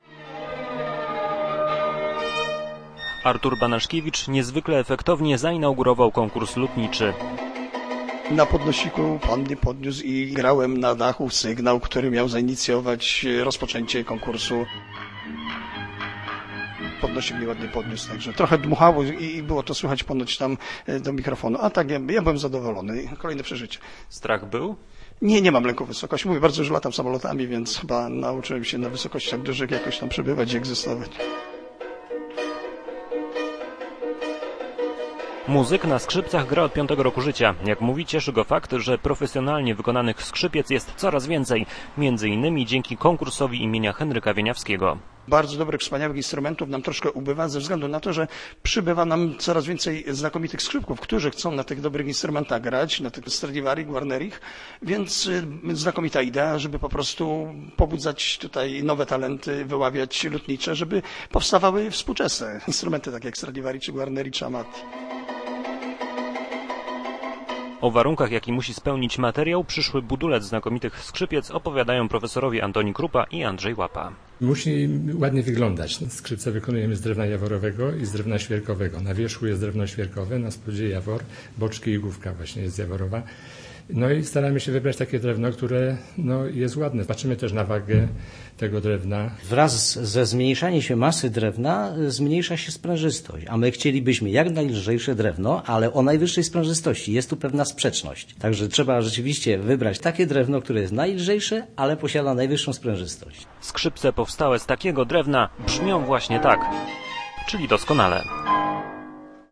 Wyjątkowa inauguracja dwunastego Międzynarodowego Konkursu Lutniczego miała miejsce w Poznaniu.
Ciąg dalszy koncertu miał miejsce w Muzeum Instrumentów Muzycznych.